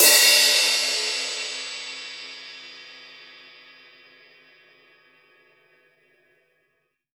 Index of /90_sSampleCDs/USB Soundscan vol.10 - Drums Acoustic [AKAI] 1CD/Partition C/03-GATEKIT 3
GATE3CRSH1-R.wav